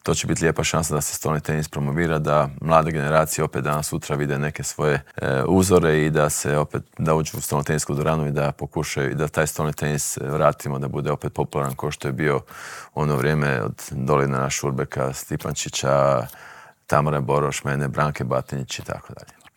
U dvorani Krešimira Ćosića na Višnjiku očekuje nas ekipno prvenstvo Europe, a u Intervjuu tjedna Media servisa ugostili smo predsjednika Hrvatskog stolnoteniskog saveza Zorana Primorca